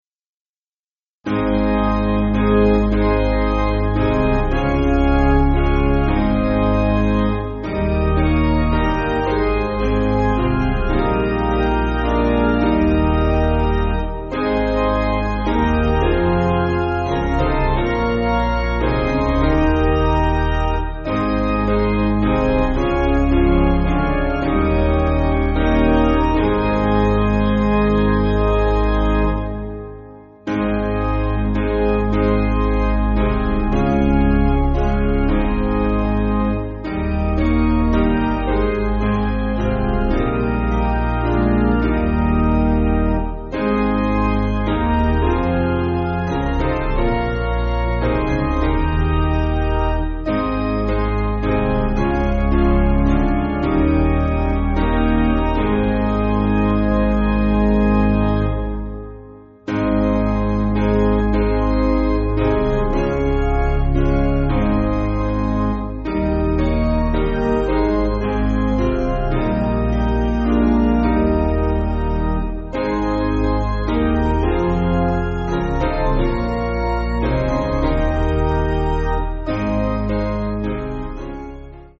Basic Piano & Organ
(CM)   4/Ab